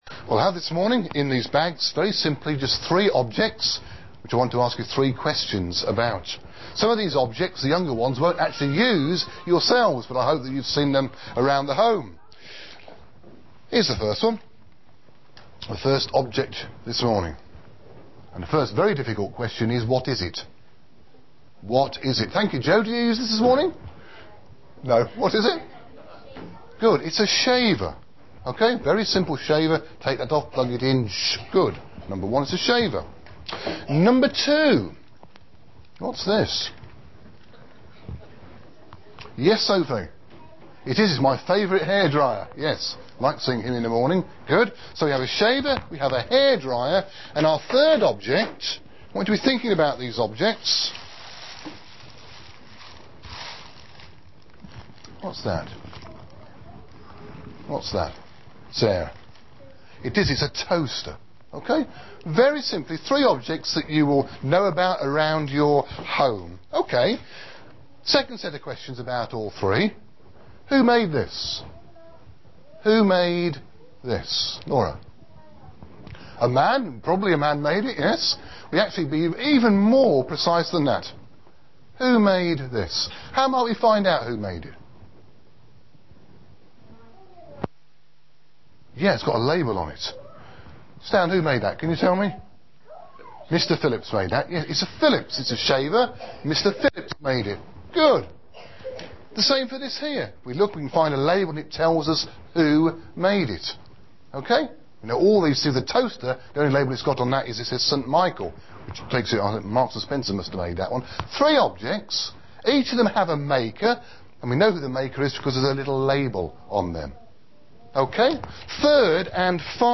Shepshed Evangelical Free Church – Children’s Talks